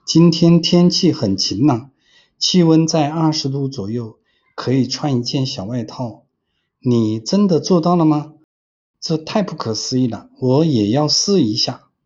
专业的AI新闻报道配音
文本转语音
新闻旁白
权威语气
资深广播记者
我们的AI提供真实、人性化的语调，清晰地播报新闻，增强观众的信任感。